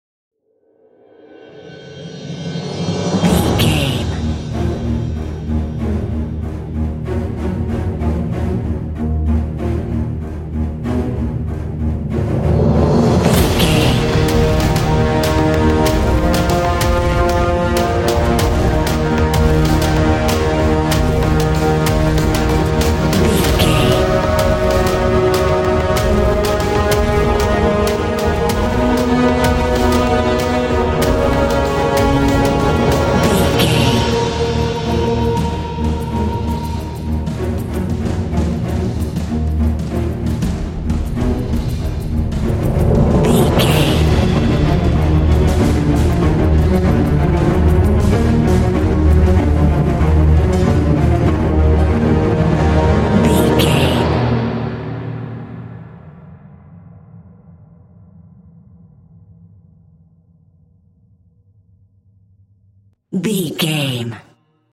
Aeolian/Minor
D♯
anxious
chaotic
dramatic
aggressive
orchestra
epic
dark